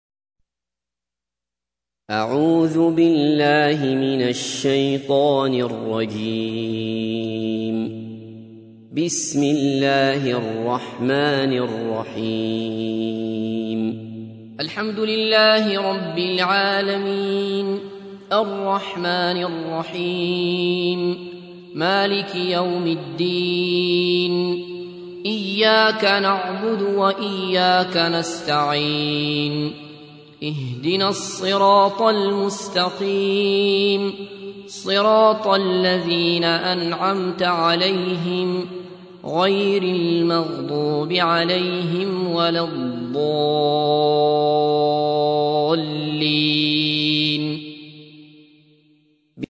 تحميل : 1. سورة الفاتحة / القارئ عبد الله بصفر / القرآن الكريم / موقع يا حسين